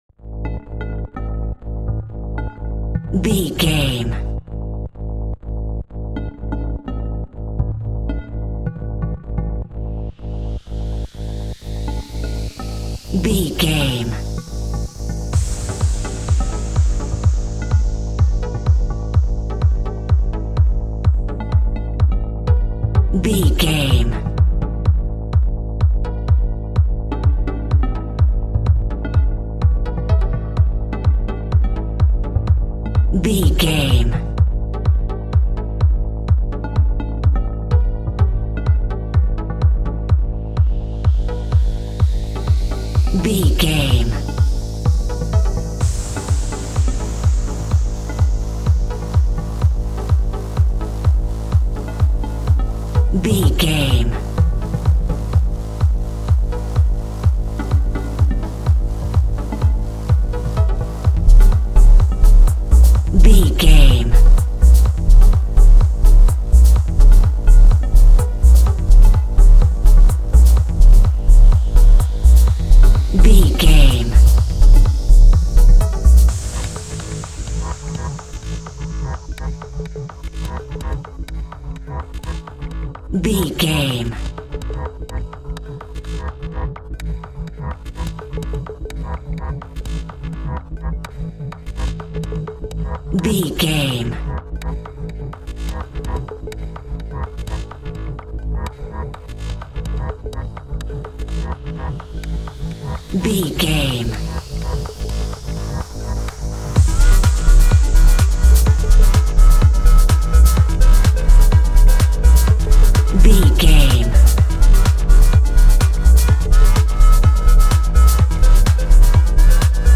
In-crescendo
Aeolian/Minor
G#
Fast
energetic
uplifting
hypnotic
industrial
groovy
drum machine
synthesiser
house
techno
trance
synth leads
synth bass
upbeat